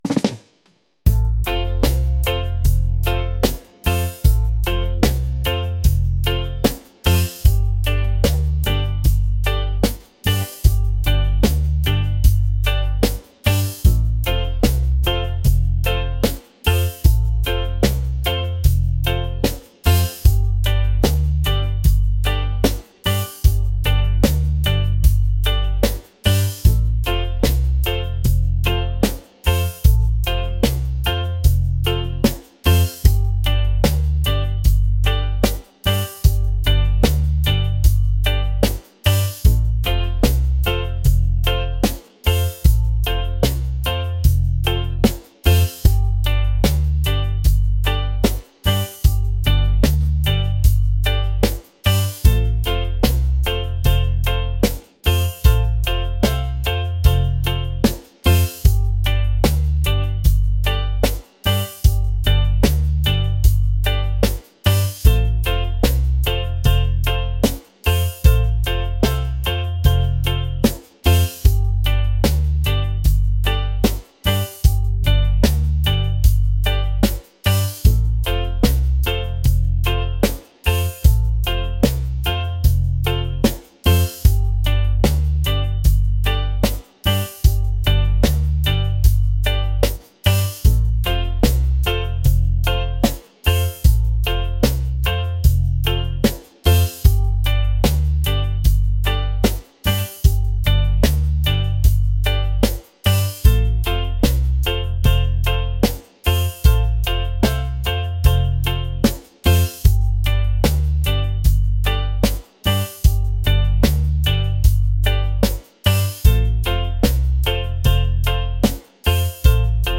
laid-back | mellow | reggae